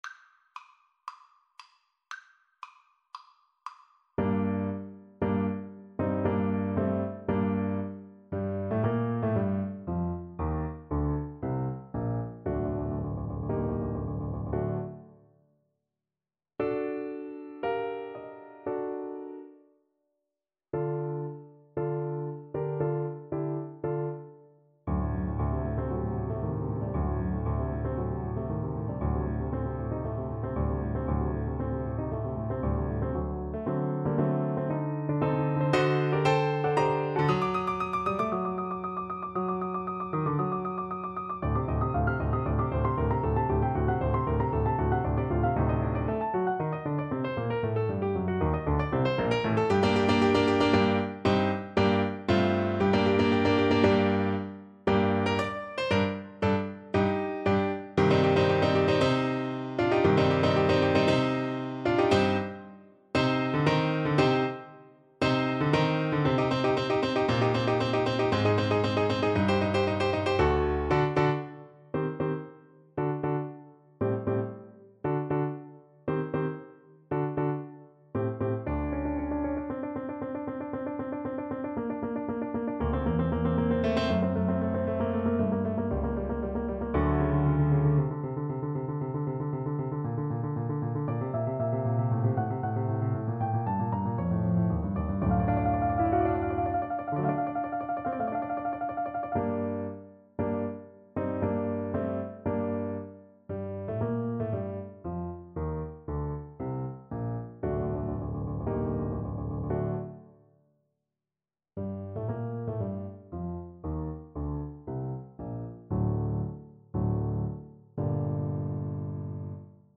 Play (or use space bar on your keyboard) Pause Music Playalong - Piano Accompaniment Playalong Band Accompaniment not yet available reset tempo print settings full screen
G minor (Sounding Pitch) D minor (French Horn in F) (View more G minor Music for French Horn )
4/4 (View more 4/4 Music)
Allegro =116 (View more music marked Allegro)
Classical (View more Classical French Horn Music)